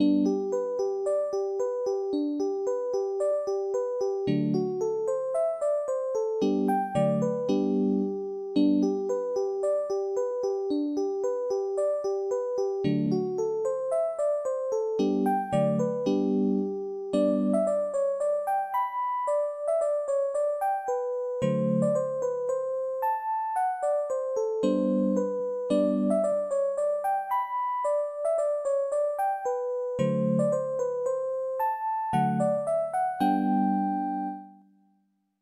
Polka - vals - hopsa - march - andet